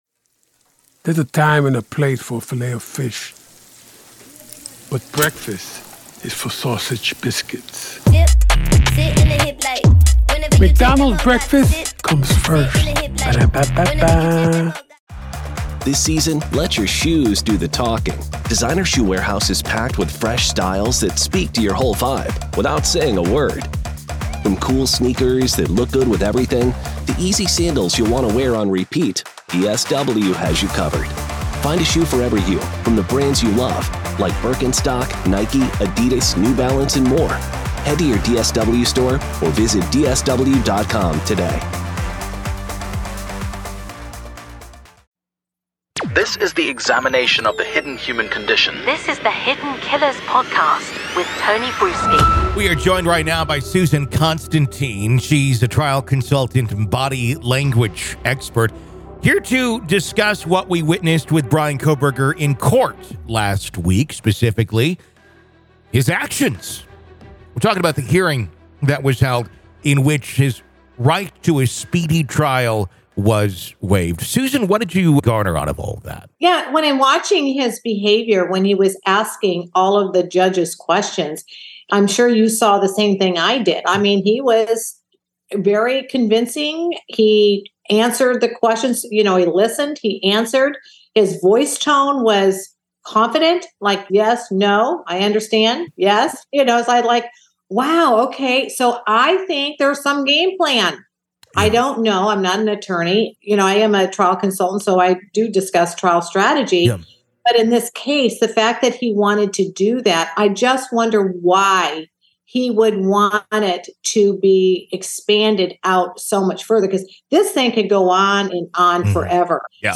True Crime Today | Daily True Crime News & Interviews / What Does Kohberger's Body Language Tell Us About His Mind?